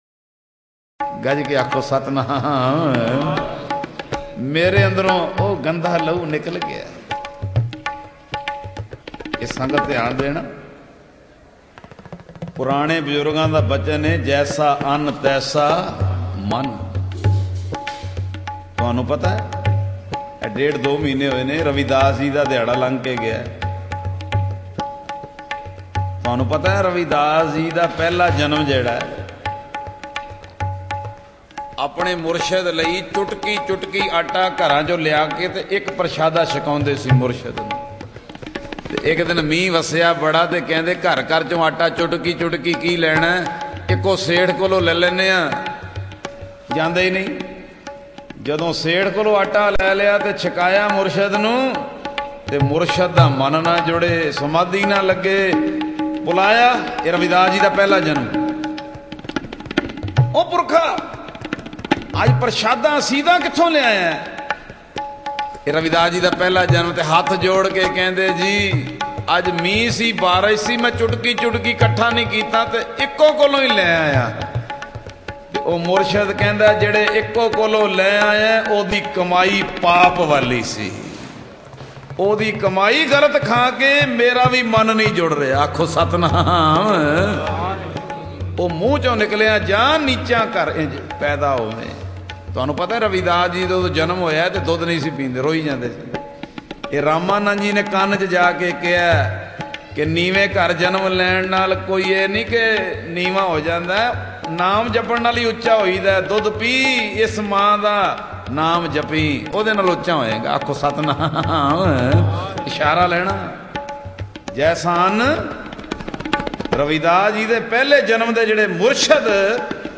Shabad Gurbani